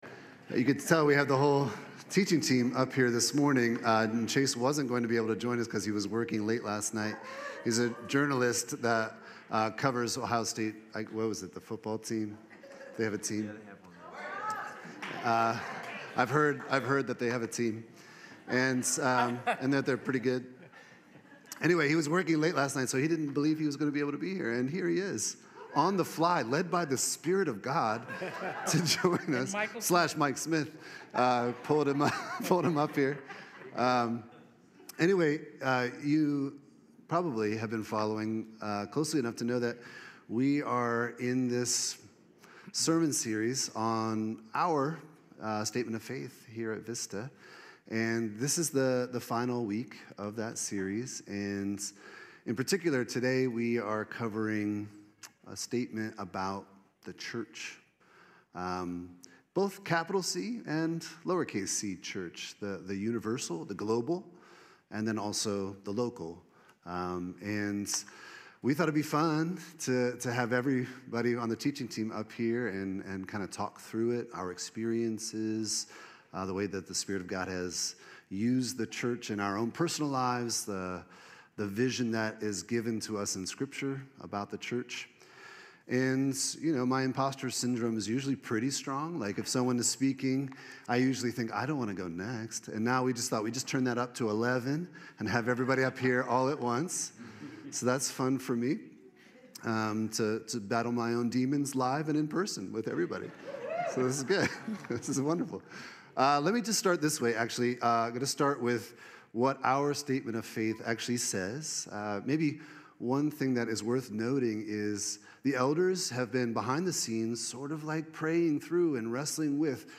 November 16, 2025 | Vista Teaching Team Panel